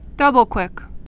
(dŭbəl-kwĭk)